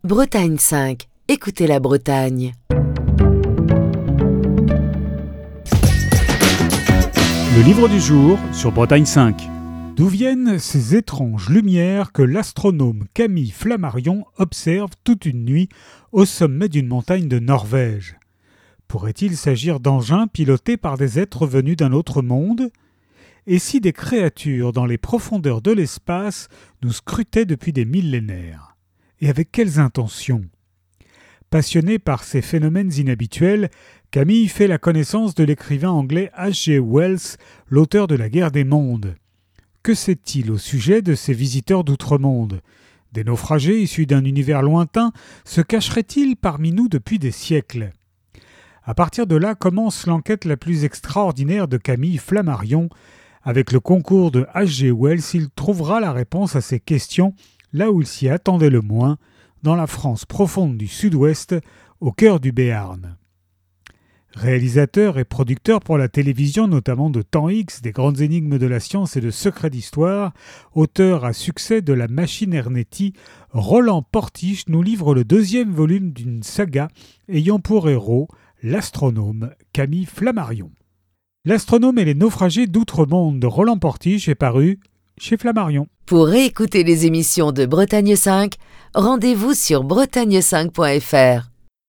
Chronique du 4 juin 2025.